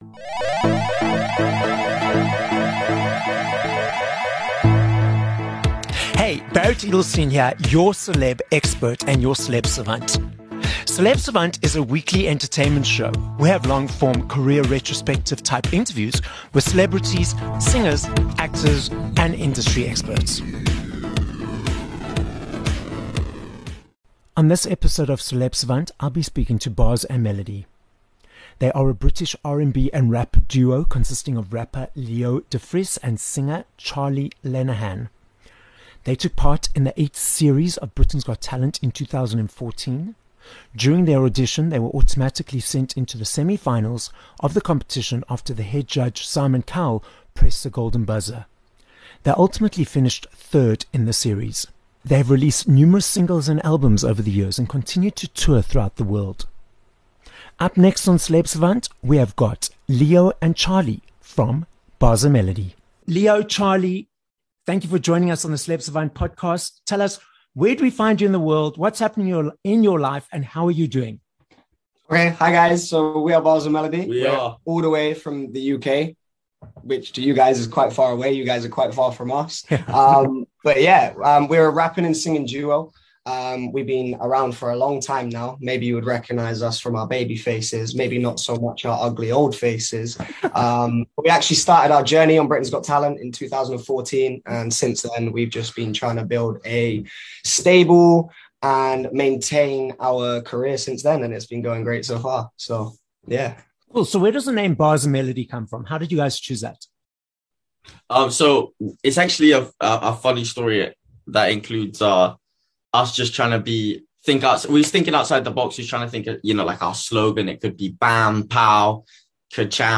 7 Nov Interview with Bars and Melody